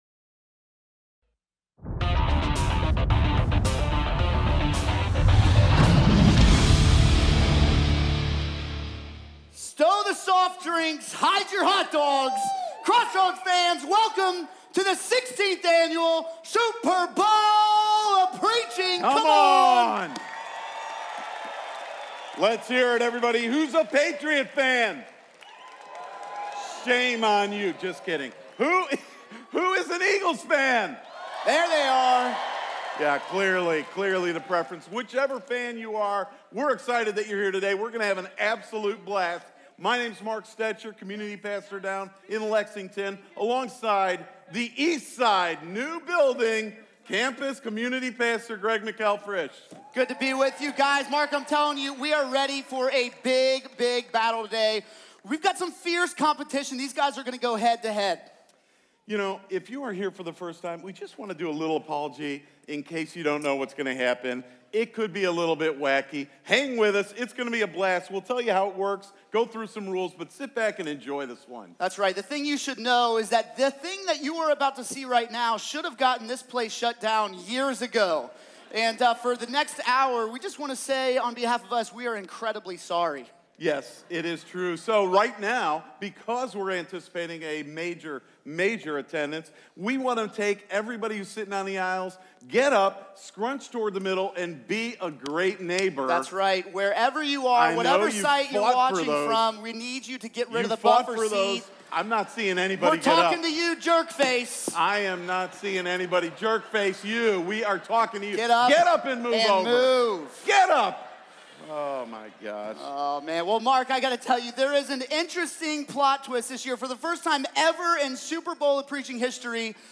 It will be loud, it will be unpredictable, and it probably should have gotten us shut down years ago. Come join us as we talk about leaving your mark at the Super Bowl of Preaching!